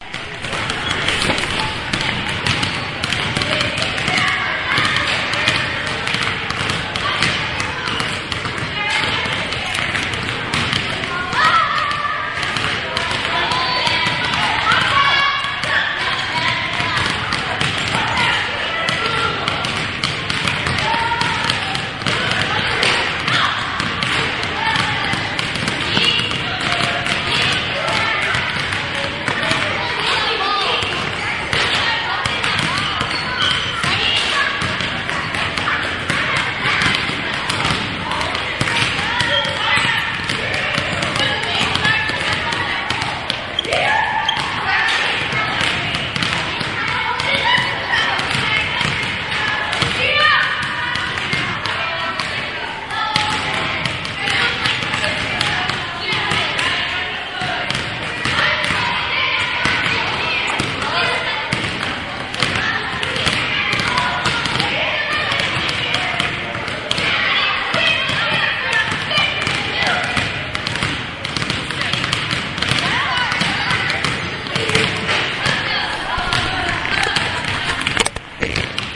真实世界的记录 " 室内篮球赛
我讨厌这种混响，但也许你们中的一些人会发现它很有用，同时将它同步到某种大型室内空间。他们的讲话不是很好理解，我自己几乎听不懂他们说的每五个字。